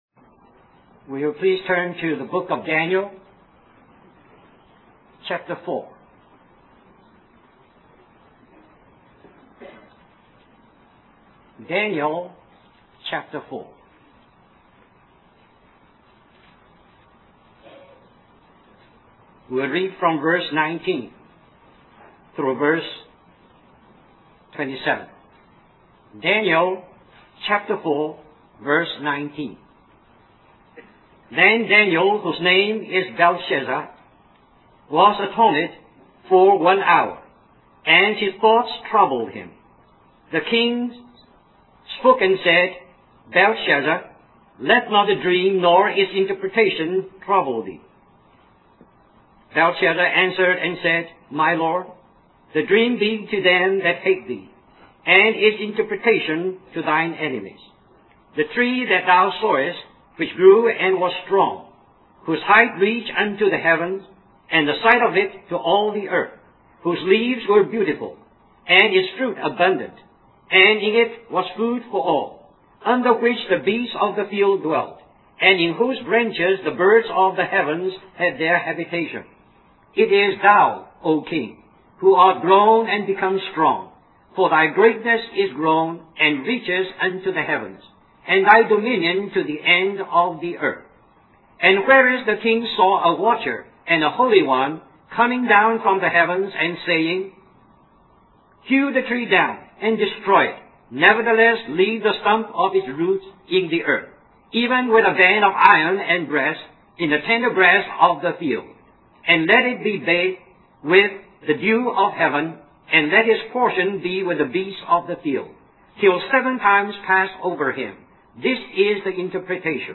A collection of Christ focused messages published by the Christian Testimony Ministry in Richmond, VA.
1986 Christian Family Conference Stream or download mp3 Summary This message is also printed in booklet form under the title